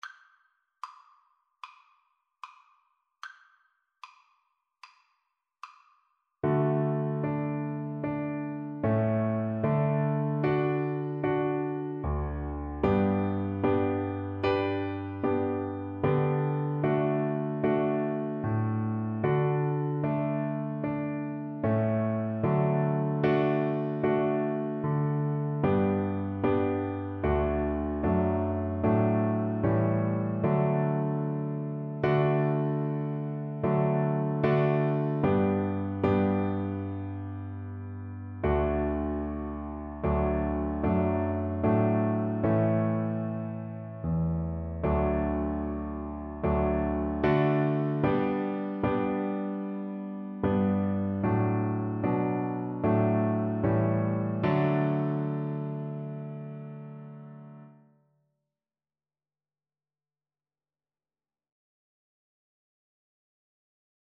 Christian
4/4 (View more 4/4 Music)
Classical (View more Classical Violin Music)